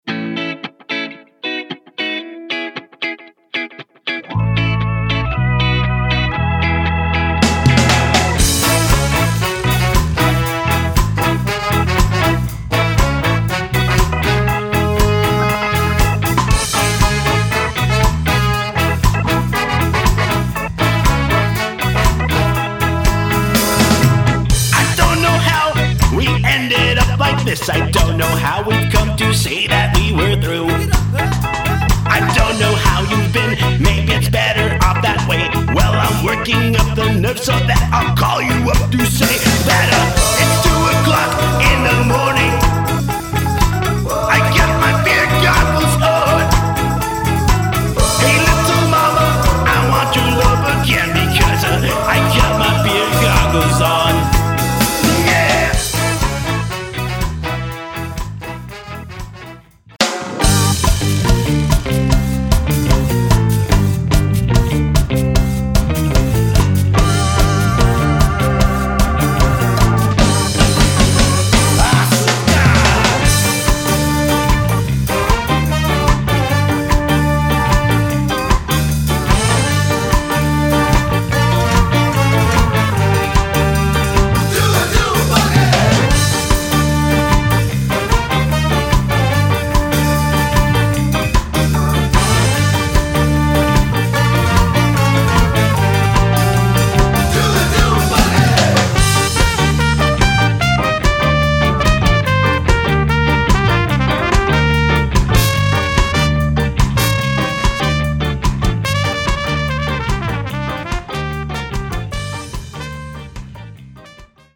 punk ska
skapunk